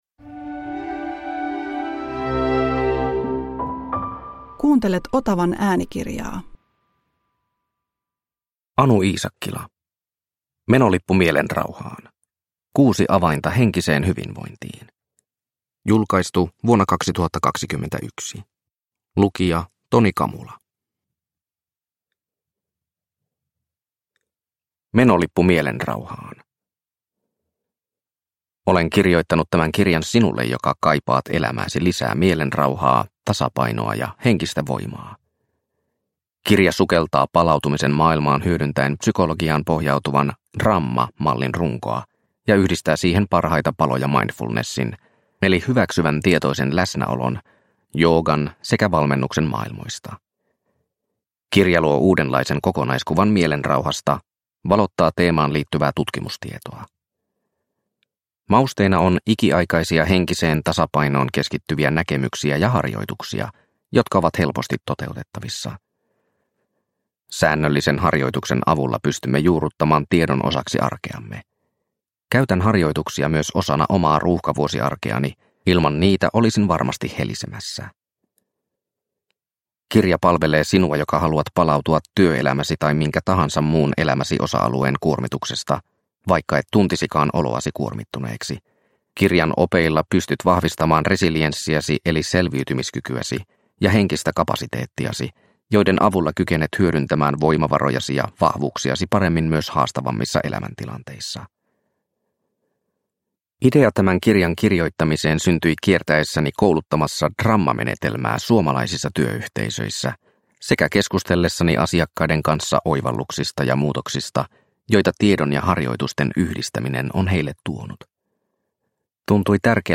Menolippu mielenrauhaan – Ljudbok – Laddas ner